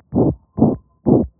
Mittelgradiges systolisches Herzgeräusch: Mitralregurgitation